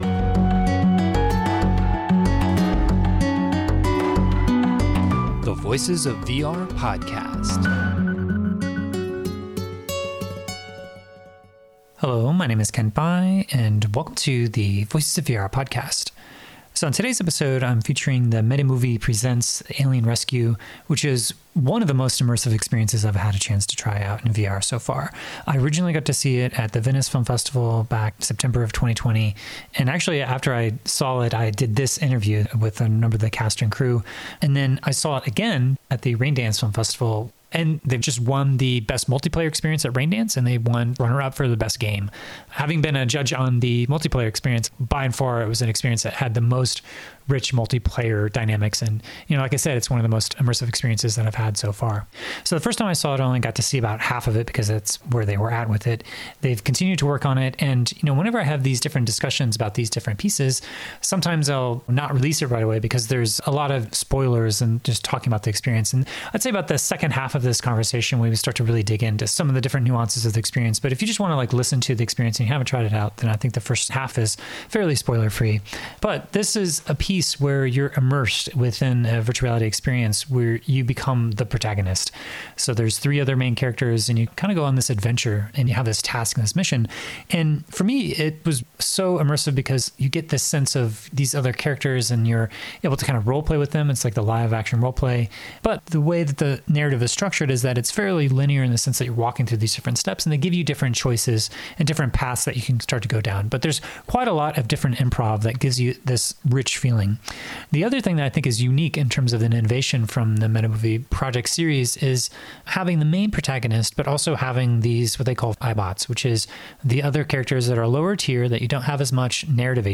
I had a chance to talk with most of the cast and crew after I saw the experience at Venice in September 2020, where I got a lot of context and history about how the series of MetaMovie Project experiments have evolved.